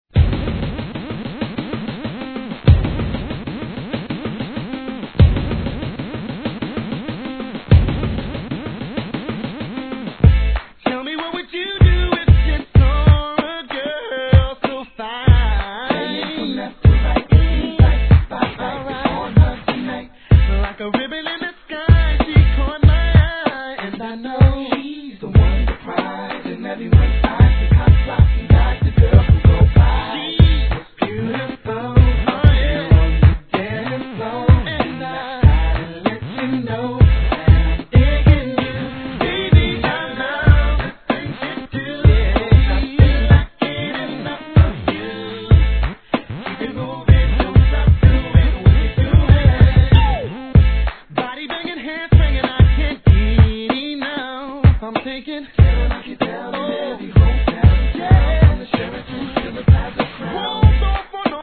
HIP HOP/R&B
2001年のマイナーMale Voacl物♪